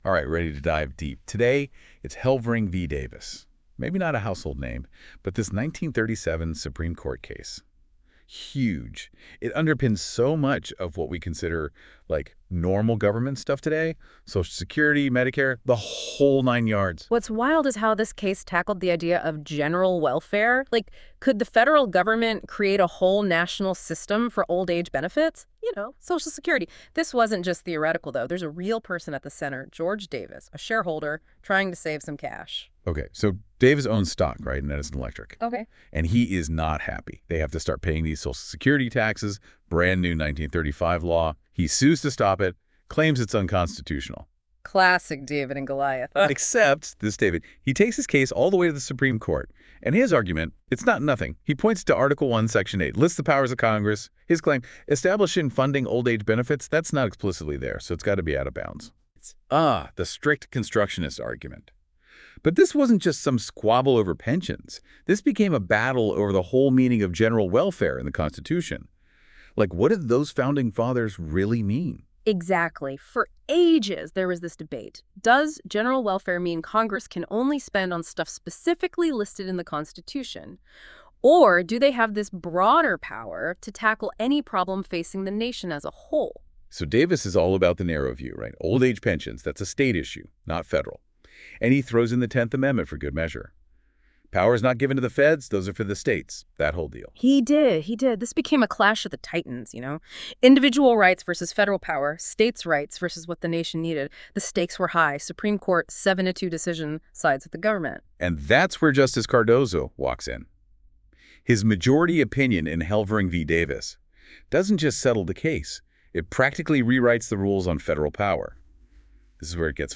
Listen to an audio breakdown of Helvering v. Davis.